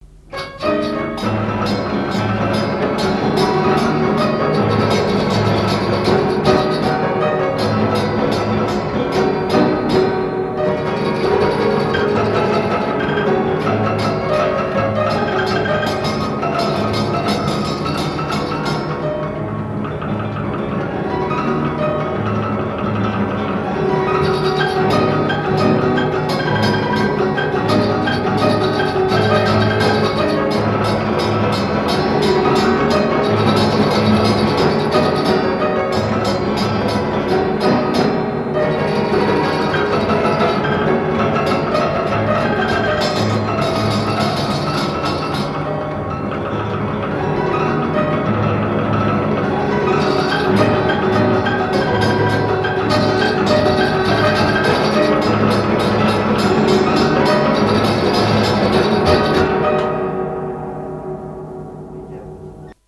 piano mécanique
Pièce musicale inédite